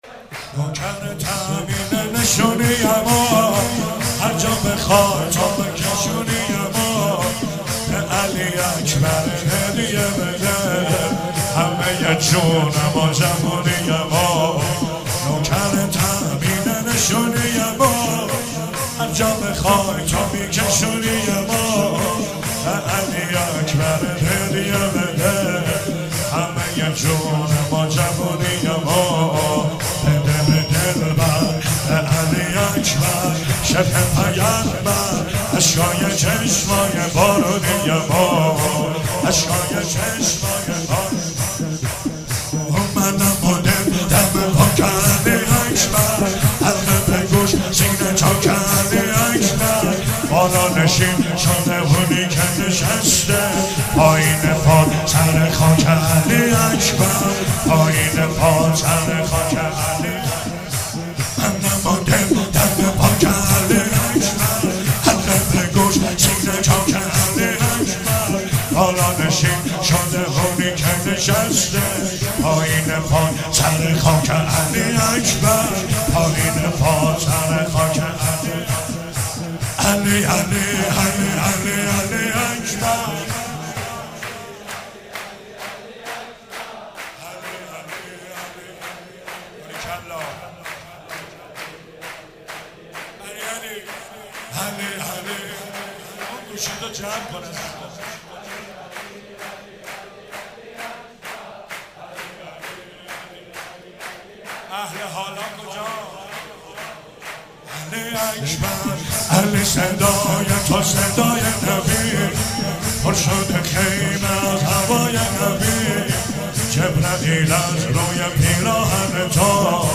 چهاراه شهید شیرودی حسینیه حضرت زینب (سلام الله علیها)